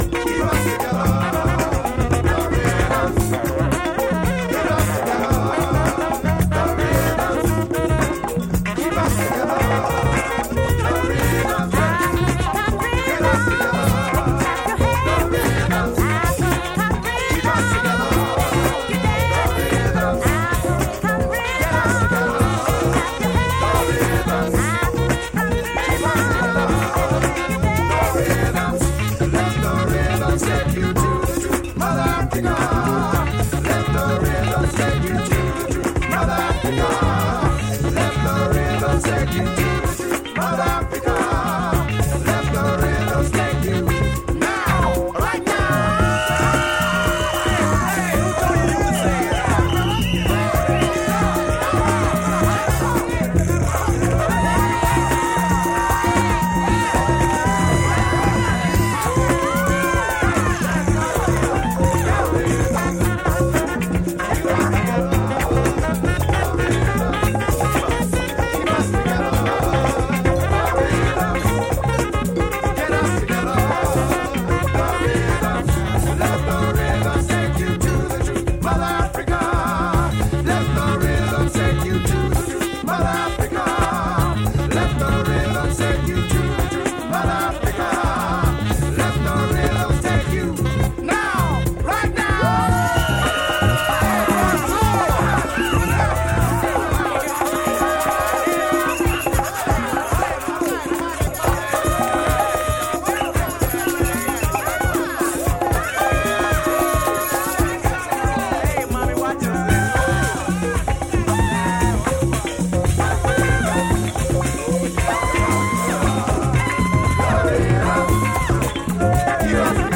which is well-known as a rare groove classic